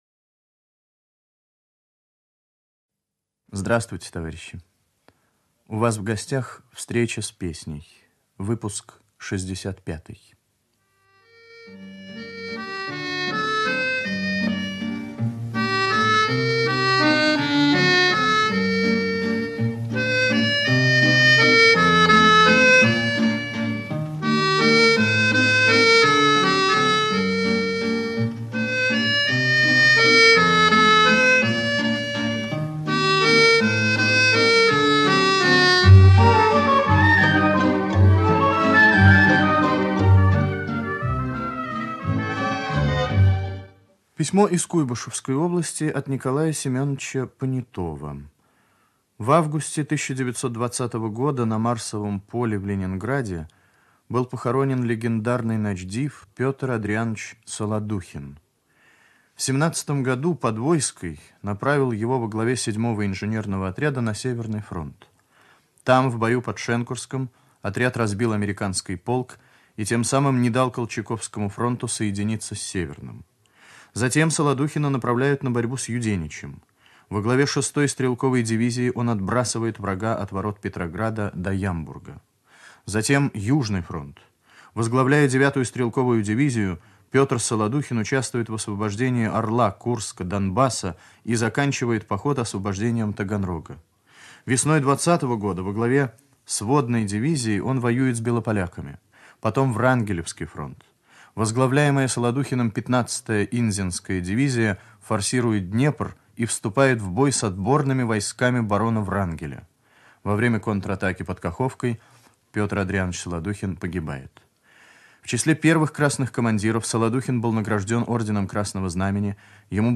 Ведущий - автор Татарский Виктор.